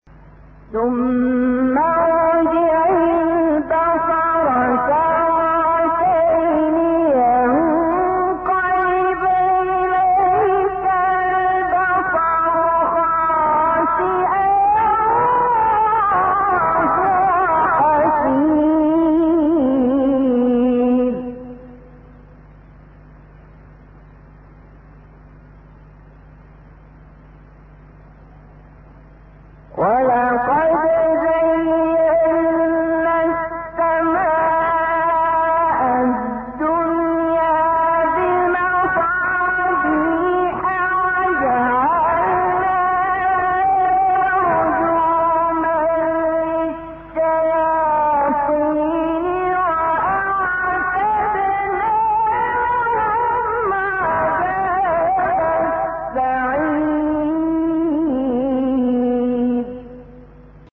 آموزش نغمات قرآنی – حجاز
5- ترکیب قرار ، جواب و جواب جواب دستگاه حجاز
ترکیب-قرار-جواب-و-جواب-جواب-دستگاه-حجاز.mp3